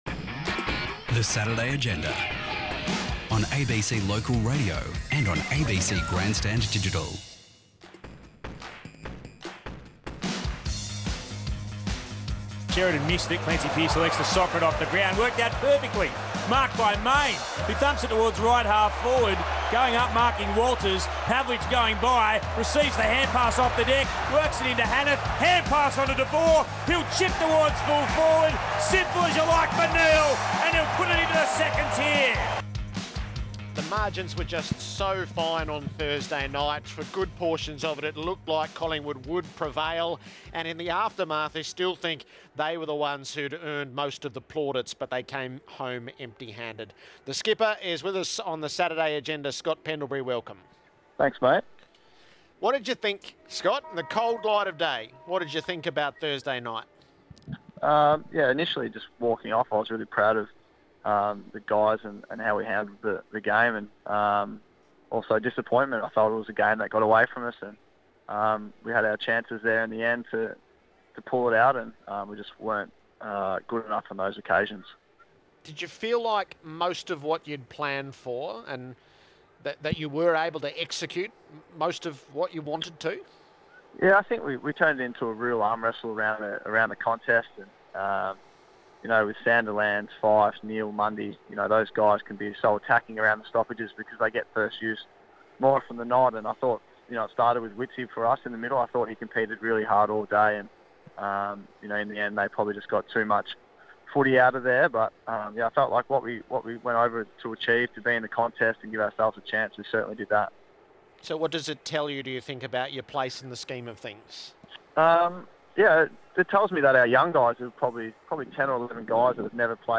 Listen to captain Scott Pendlebury take questions from the Saturday Agenda team on 774 ABC Grandstand on Saturday 27 June 2015.